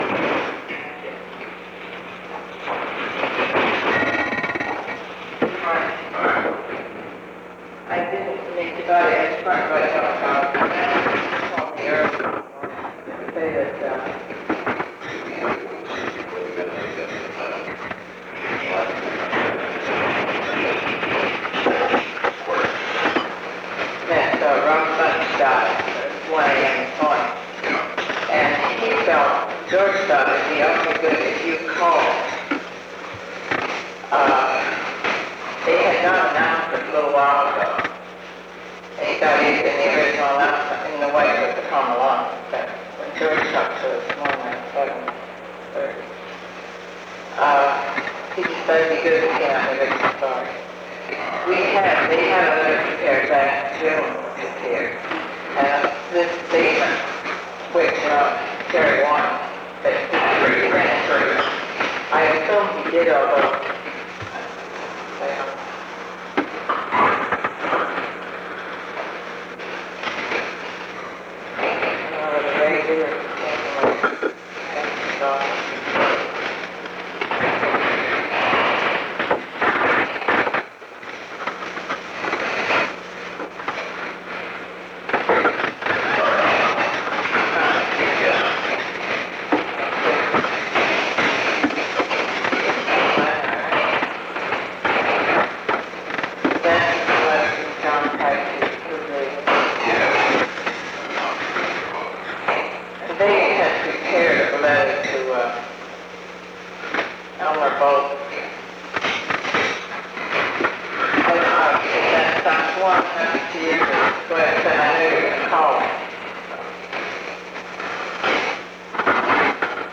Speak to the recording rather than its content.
Secret White House Tapes Location: Oval Office